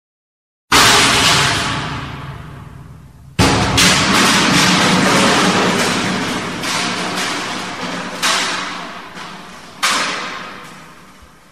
Crash Sound Klingelton kostenlos herunterladen
Kategorien Soundeffekte